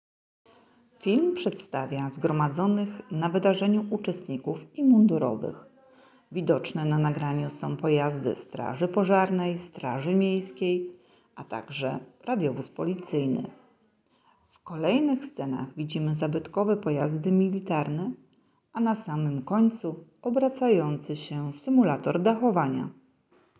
15 czerwca 2024 roku w siedzibie Ochotniczej Straży Pożarnej „Górniki” przy ulicy Żołnierskiej 41 w Bytomiu odbył się piknik rodzinny, pod nazwą „Piknik strażacki: Dzień Bezpieczeństwa z OSP Górniki”.